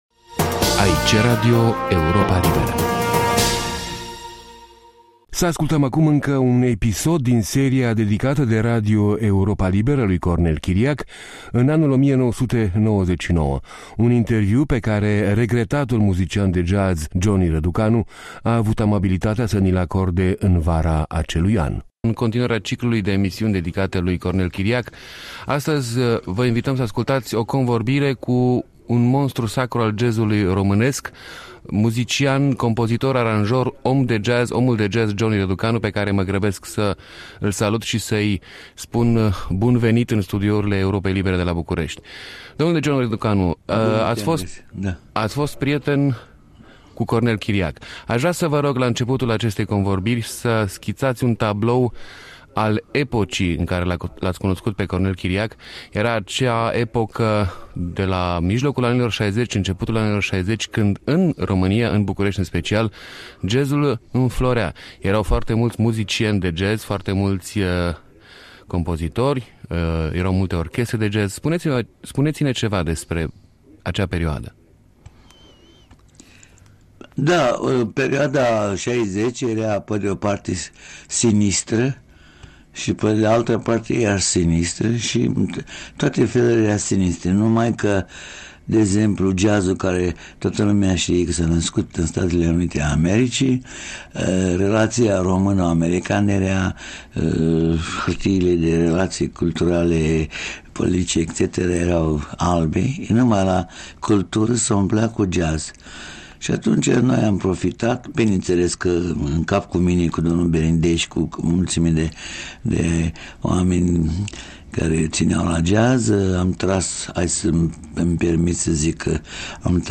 Să ascultăm acum încă un episod din seria dedicată de Radio Europa Liberă lui Cornel Chiriac în anul 1999 - un interviu pe care regretatul muzician de jazz Johnny Răducanu a avut amabilitatea să ni-l acorde în vara acelui an.